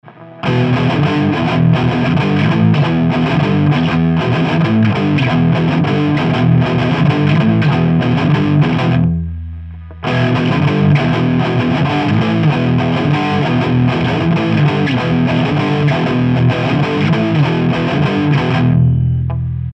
Rock_1_EPI_Custom.mp3